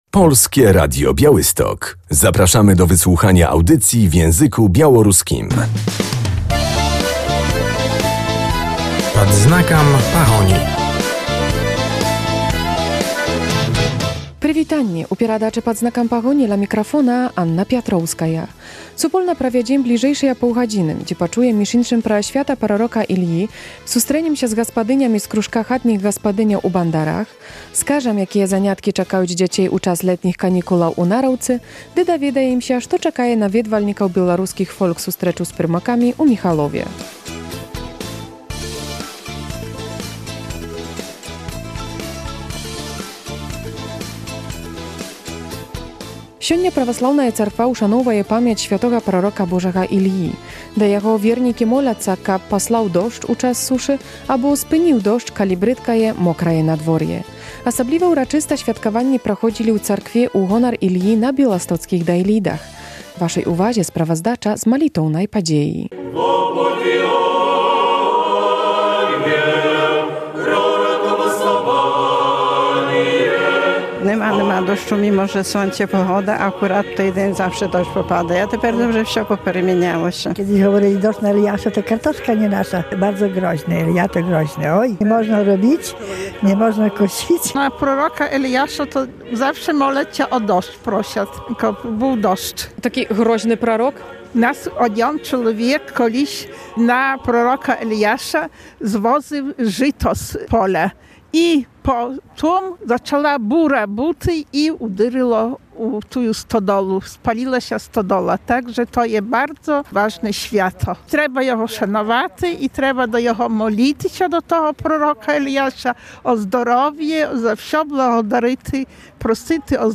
W audycji usłyszymy relację z uroczystości ku czci św. proroka Eliasza w Białymstoku.